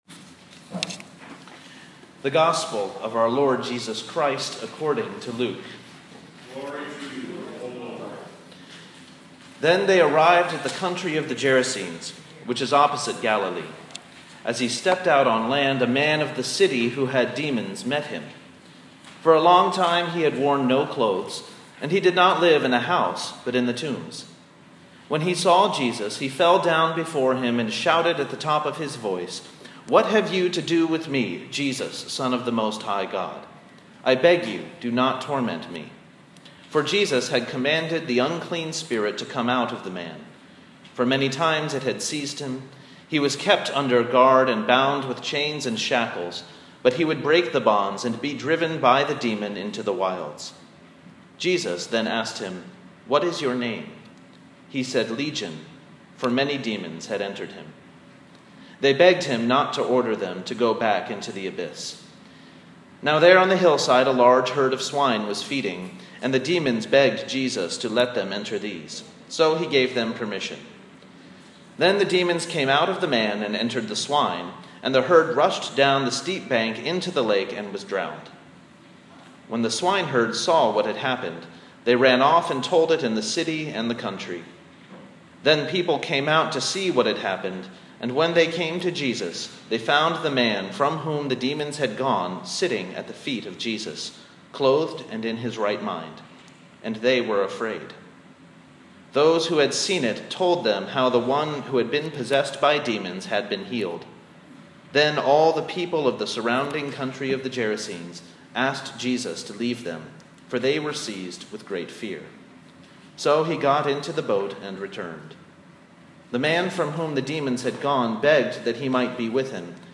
sermon-pentecost-5-c-2016.m4a